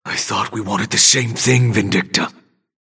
Grey Talon voice line - I thought we wanted the same thing, Vindicta!